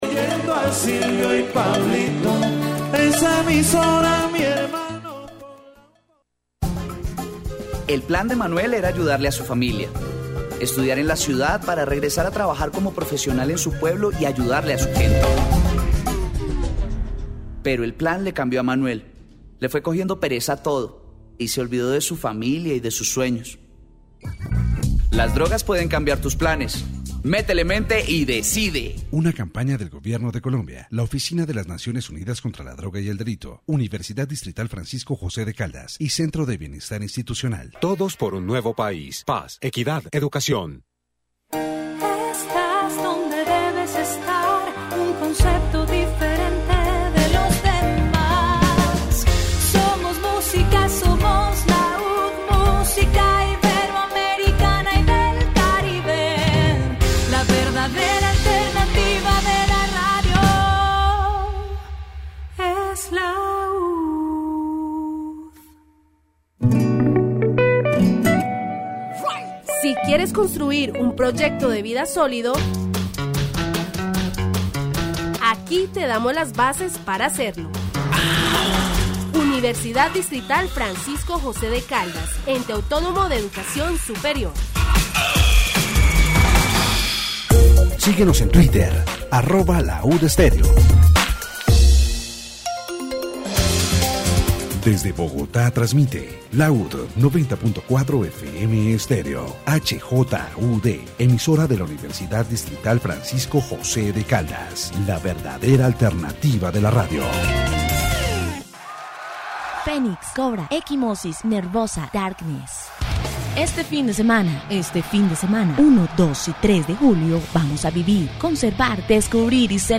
Special broadcast of Rock al Parque 2017 from the Bio stage, where LAUD 90.4 FM radio presents various bands and conducts interviews.